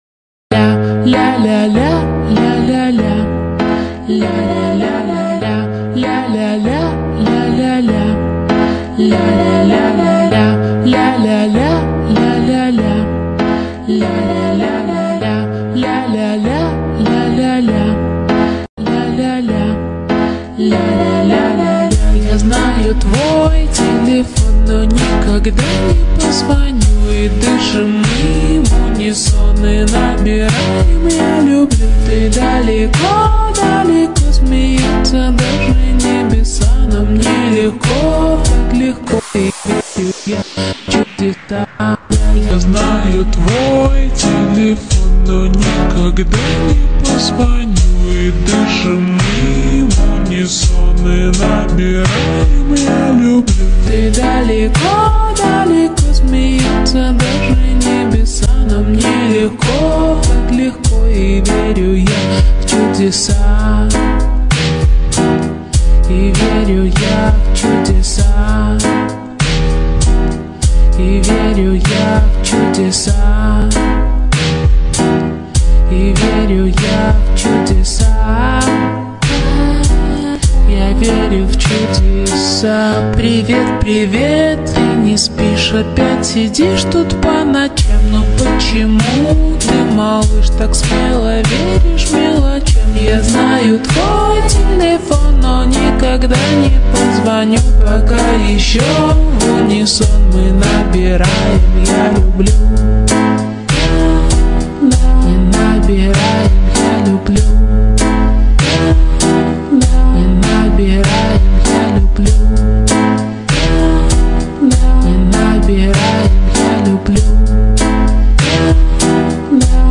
Slowed Reverb TikTok Remix